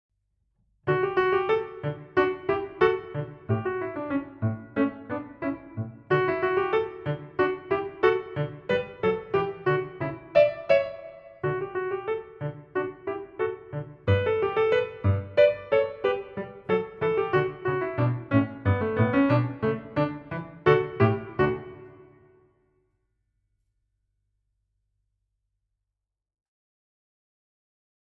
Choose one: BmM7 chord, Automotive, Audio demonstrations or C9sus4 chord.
Audio demonstrations